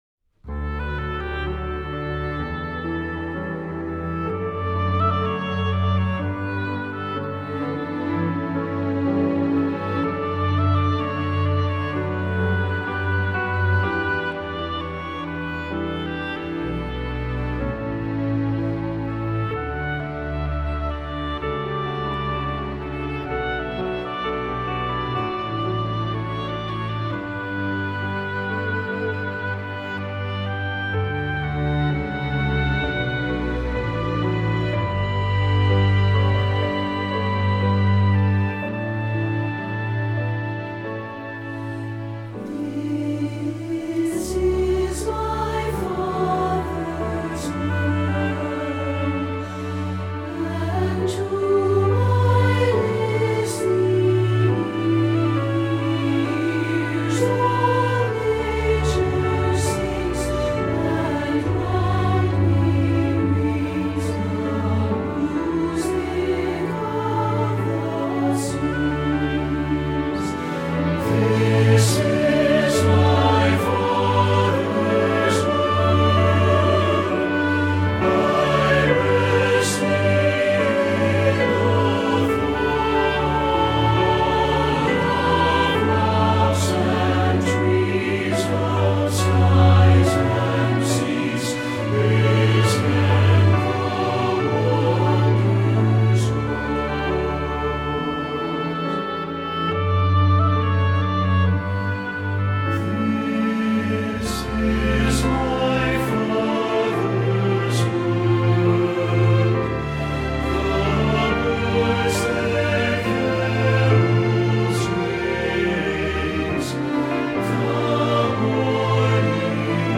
Voicing: SATB and Oboe